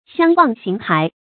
相忘形骸 xiāng wàng xíng hái
相忘形骸发音
成语注音ㄒㄧㄤ ㄨㄤˋ ㄒㄧㄥˊ ㄏㄞˊ